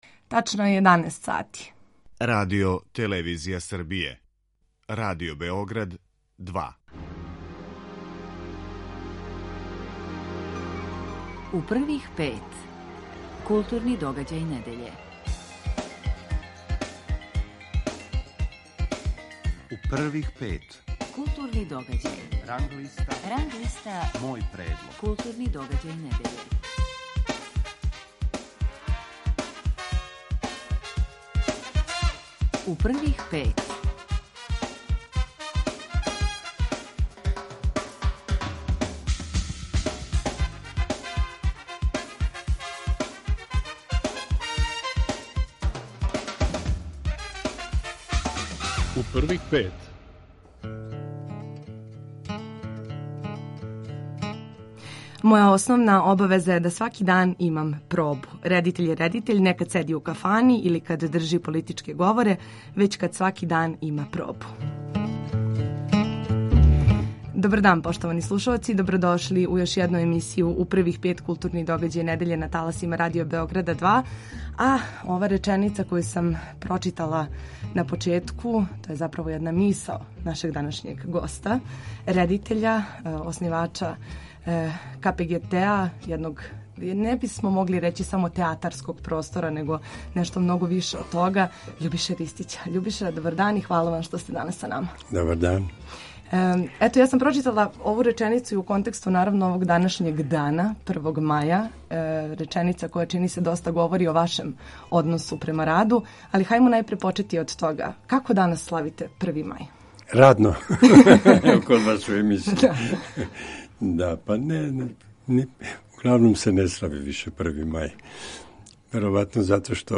Гост емисије је Љубиша Ристић.